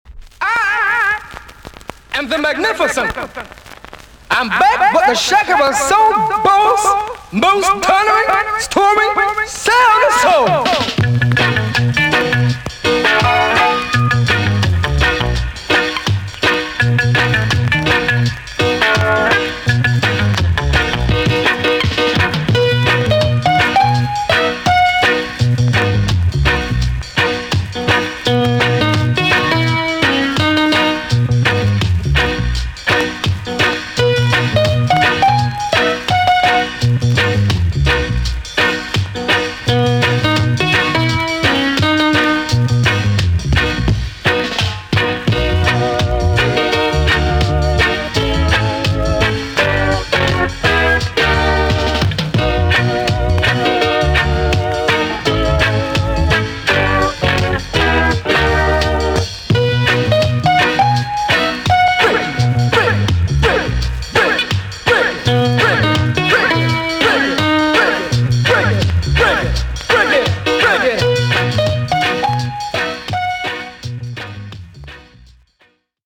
TOP >REGGAE & ROOTS
VG+ 少し軽いチリノイズがあります。